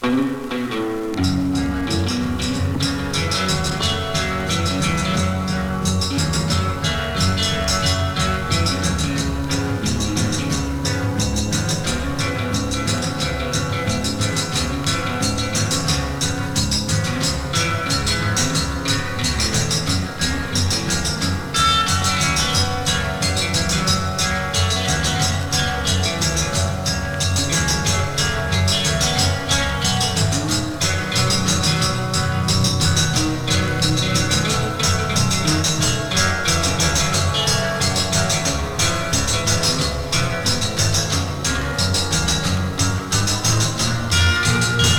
どれもが踊れて、音もやたら良い爽快快活な1枚です。
Rock'N'Roll, Surf, Limbo 　USA　12inchレコード　33rpm　Stereo